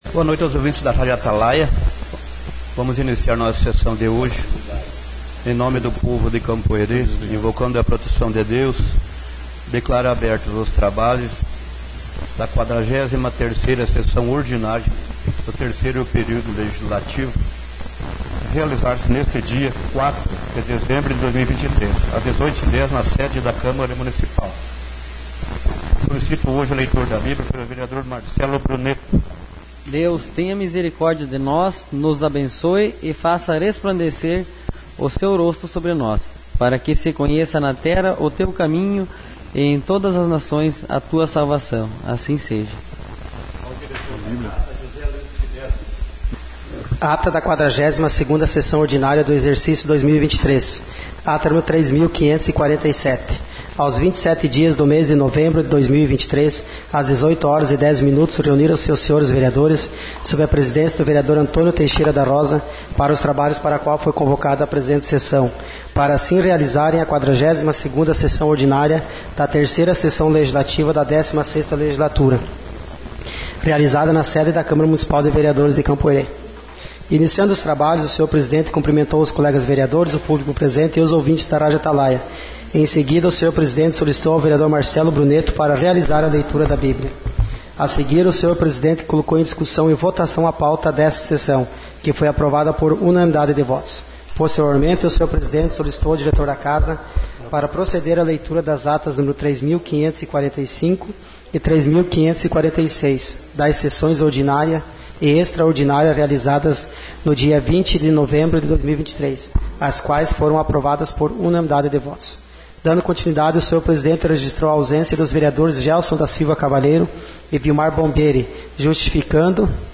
Sessão Ordinária 04/12